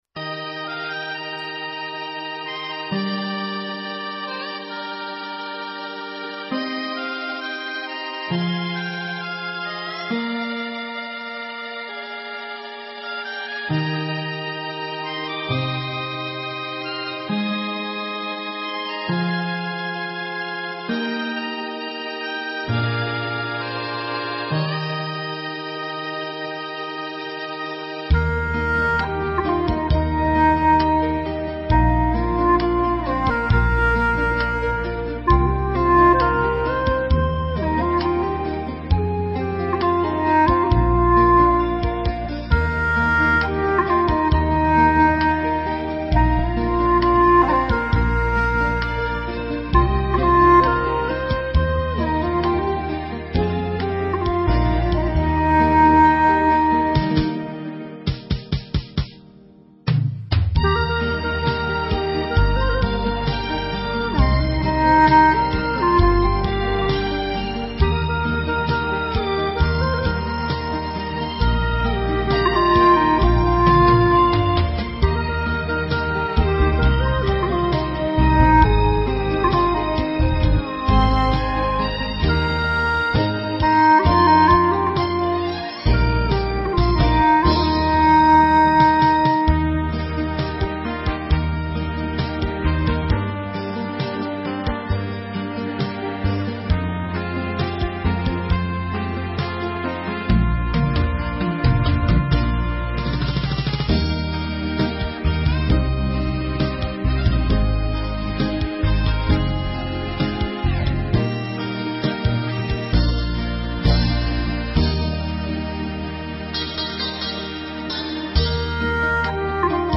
调式 : 降E 曲类 : 流行